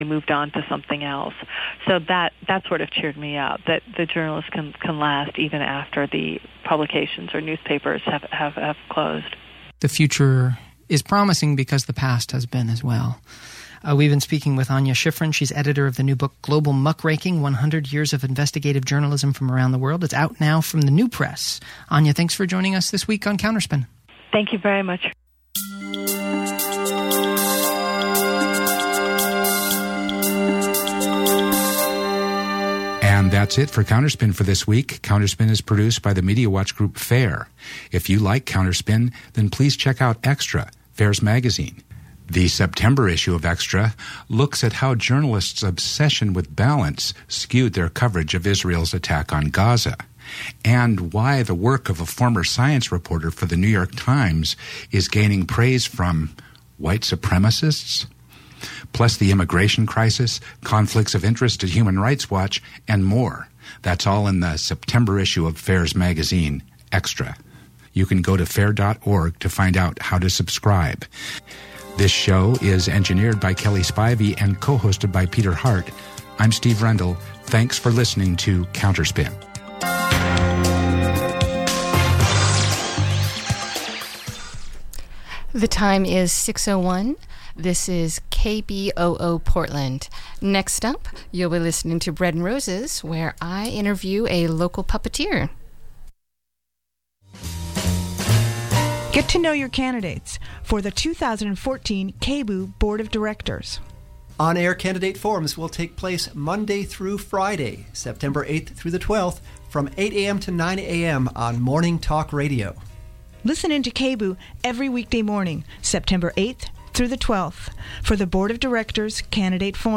Tune in this Friday for a conversation on social justice work a...